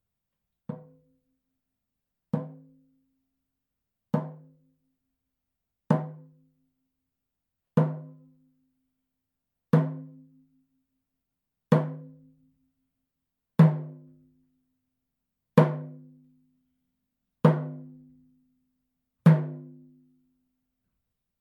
ネイティブ アメリカン（インディアン）ドラム NATIVE AMERICAN (INDIAN) DRUM 12インチ（deer 鹿）
ネイティブアメリカン インディアン ドラムの音を聴く
乾いた張り気味の音です